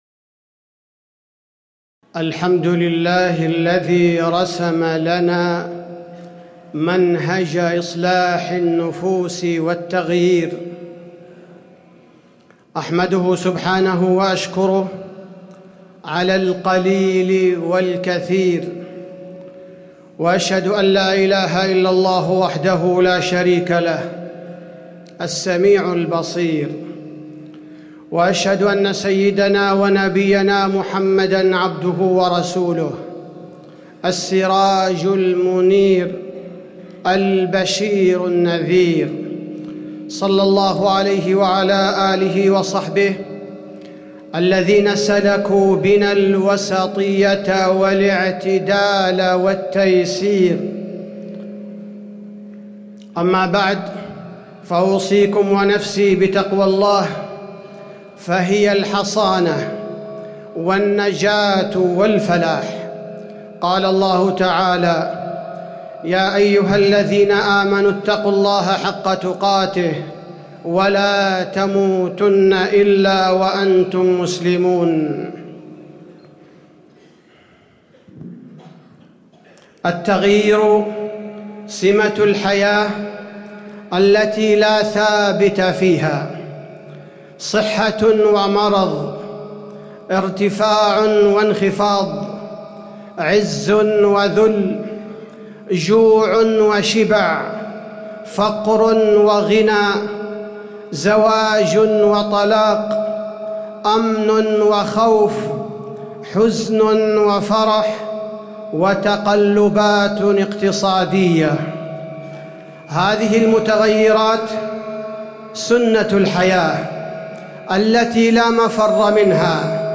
تاريخ النشر ١٠ ربيع الأول ١٤٣٨ هـ المكان: المسجد النبوي الشيخ: فضيلة الشيخ عبدالباري الثبيتي فضيلة الشيخ عبدالباري الثبيتي منهج إصلاح النفوس